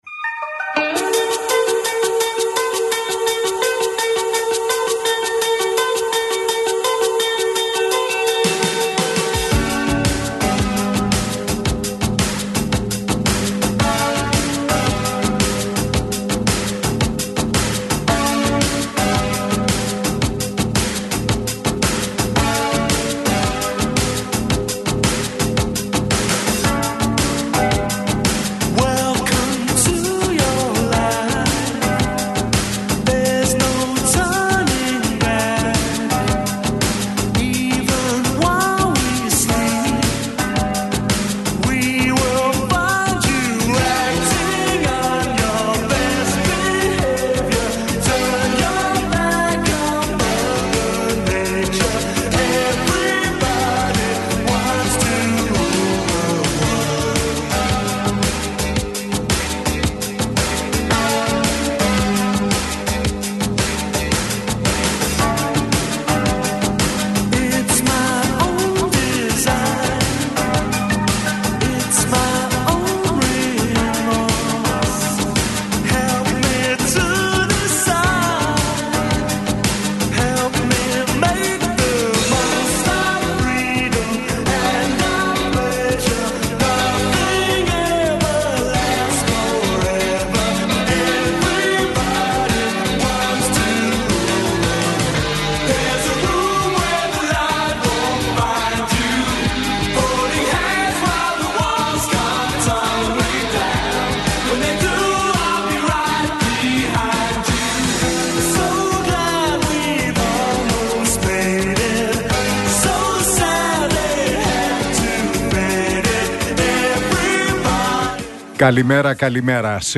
Ακούστε την εκπομπή του Νίκου Χατζηνικολάου στον RealFm 97,8, την Πέμπτη 18 Απριλίου 2024.